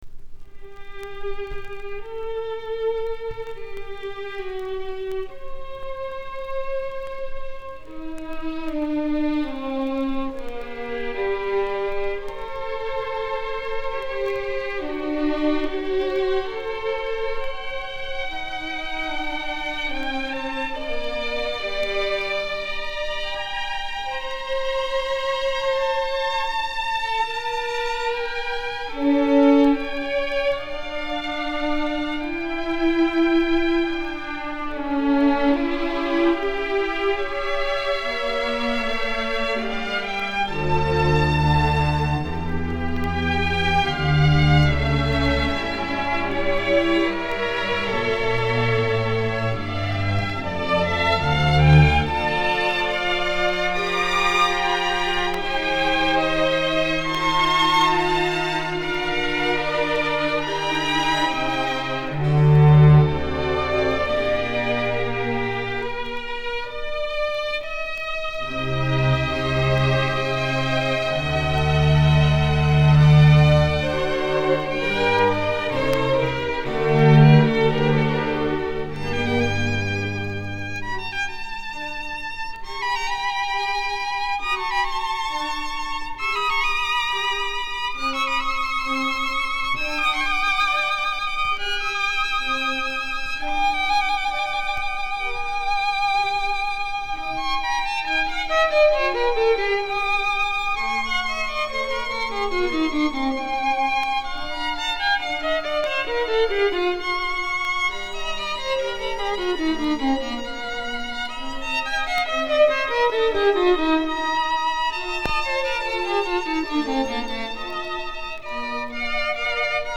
Не совсем типично для экспрессивного Вивальди.
А ВИВАЛЬДИ_АДАЖИО ИЗ КОНЦЕРТА №27.mp3